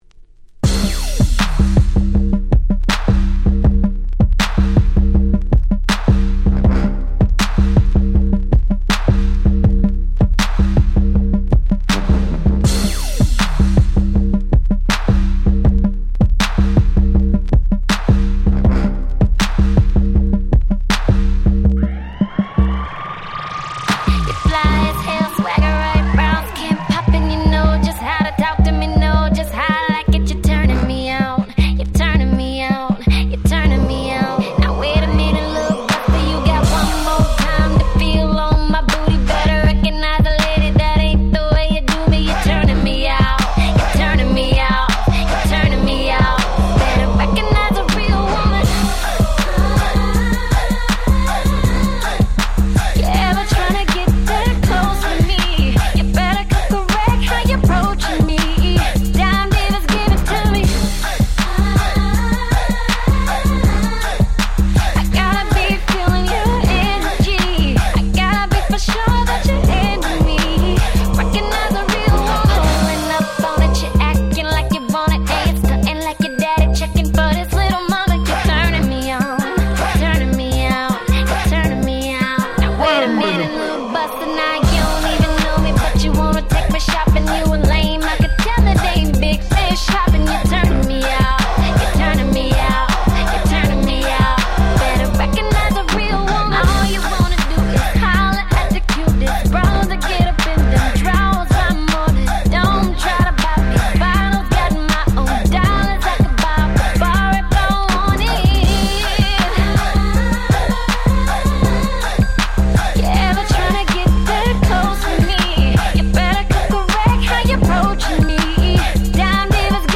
Hip Hop R&B